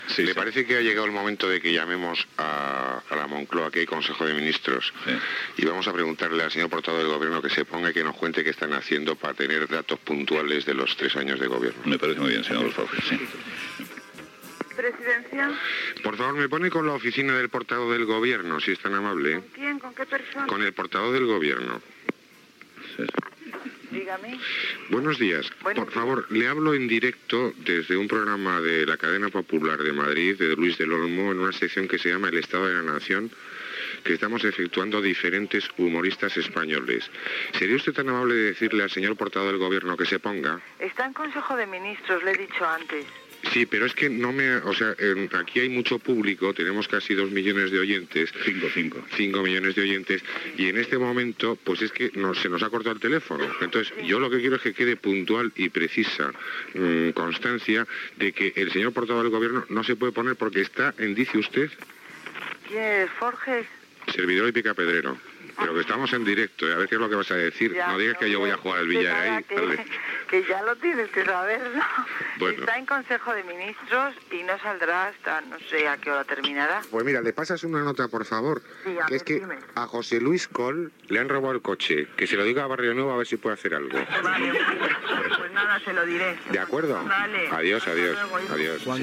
Fragment d'una trucada de Forges al portaveu del Govern espanyol des de la secció "El debate sobre el estado de la nación".
Info-entreteniment